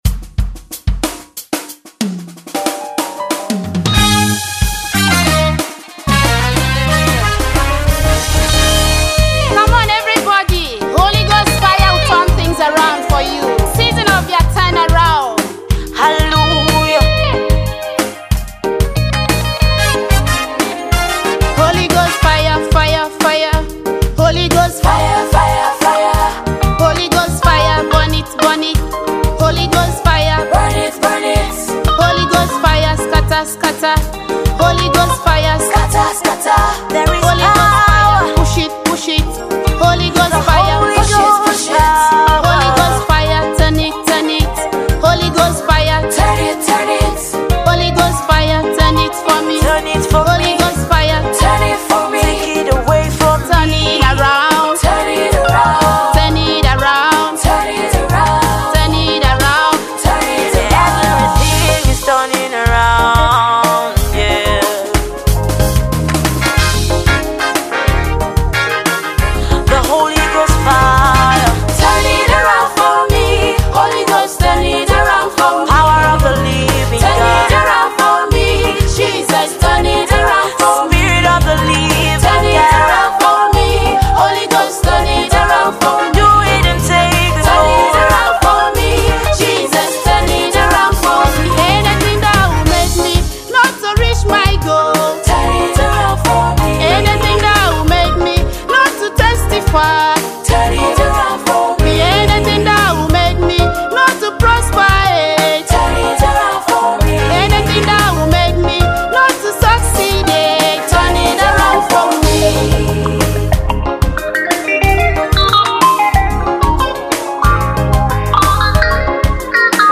the very cheerful African Gospel Singer